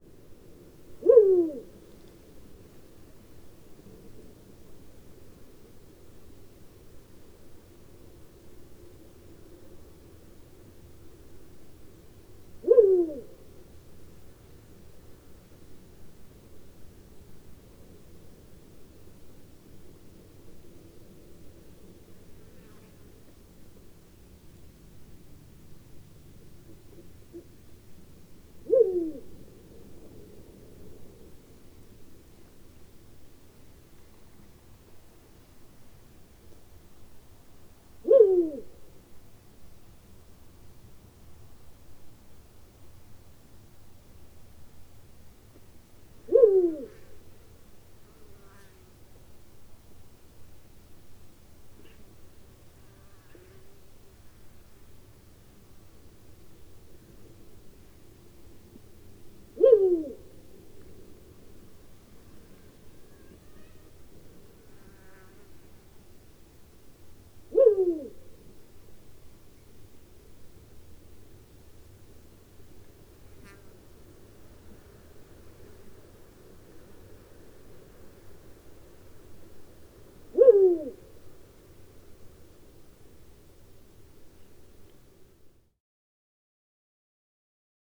Pharaoh Eagle-Owl has a very distinctive hoot consisting of a single, rapidly descending and diminishing note.
Hooting of a male.
21-Pharaoh-Eagle-Owl-Hooting-Of-Male-Once-With-Low-Grunts.wav